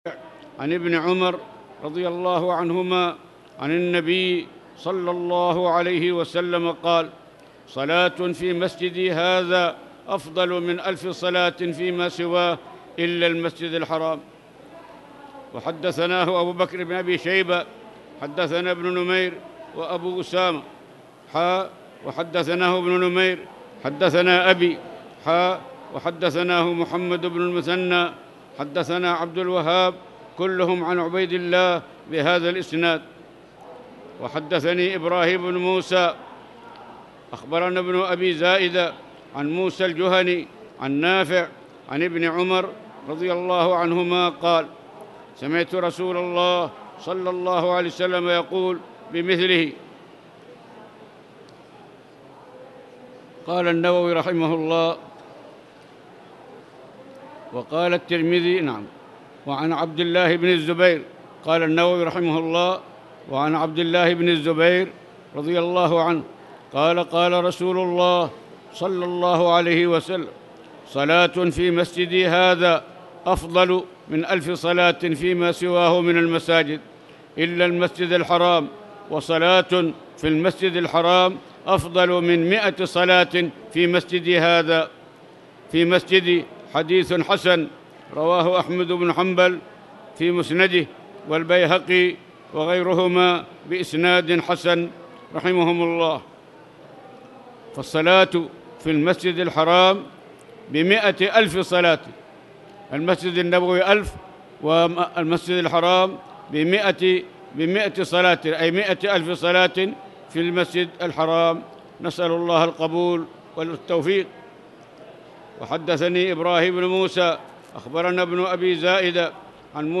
تاريخ النشر ١٤ صفر ١٤٣٨ هـ المكان: المسجد الحرام الشيخ